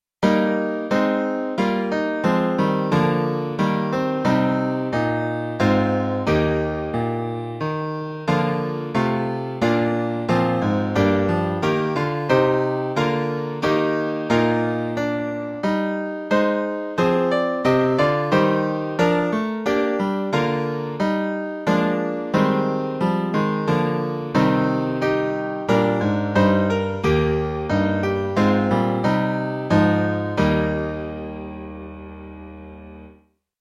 Midi file is generated with Music Publisher 8, and is modified using Anvil Studio 2013, and is piano only.
Music:Slane“, of Irish folk origin.   Listen to the hymn melody